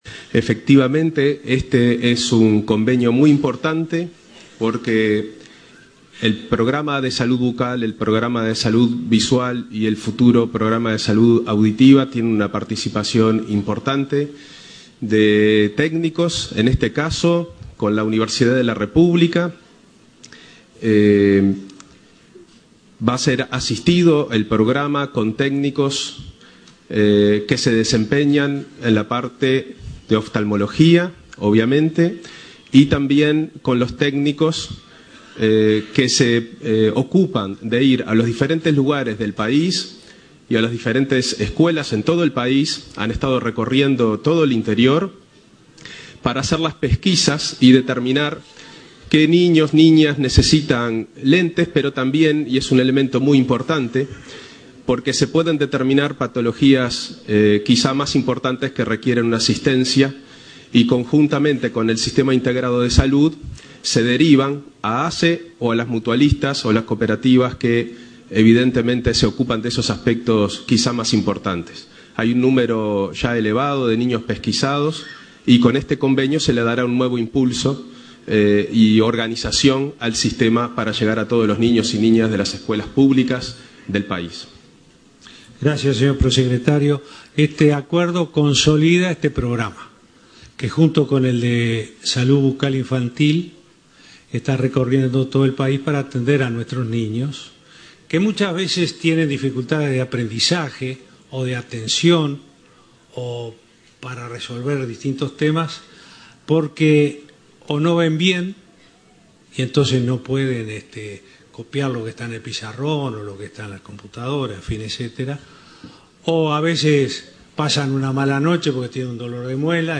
El Gobierno lanzará el próximo 1.° de marzo el Programa de Salud Auditiva, iniciativa que se sumará a los programas de Salud Bucal y Salud Visual que ya están en marcha, anunció el Presidente Vázquez, en el Consejo de Ministros abierto de Flores. El Prosecretario, Juan Andrés Roballo, anunció por su parte, un acuerdo con la Universidad de la República que consolida el Programa Salud Visual en todas las escuelas del país.